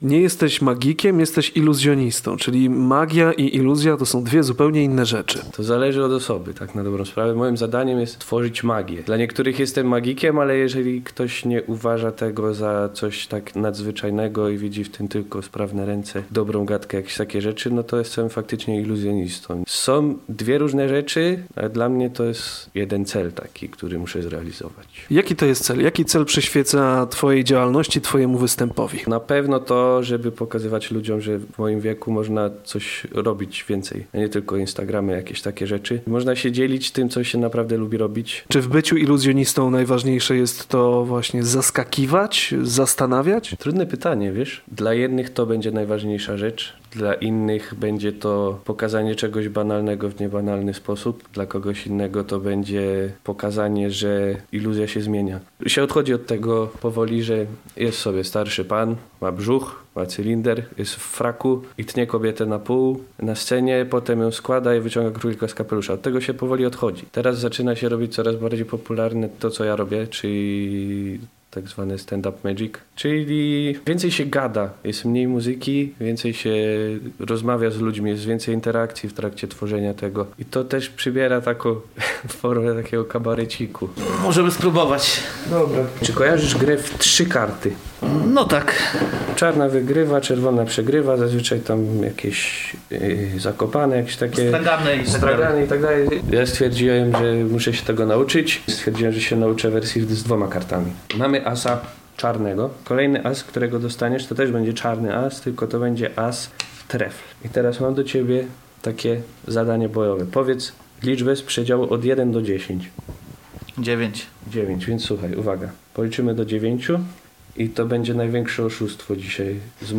Posłuchaj rozmowy z magikiem: